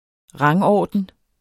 Udtale [ ˈʁɑŋ- ]